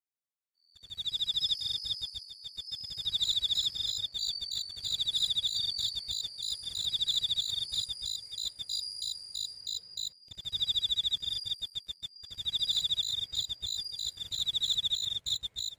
●秋の自然環境音
秋といえばいろいろとありますが、虫の音が心を癒してくれます。
• 虫の音としては、コウロギスズムシなどです。
sample-sound-of-insects-in-automn.mp3